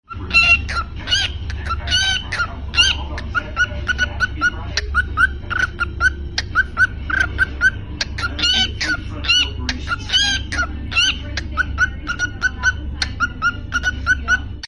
cookie cockatiel
cookie-cockatiel.mp3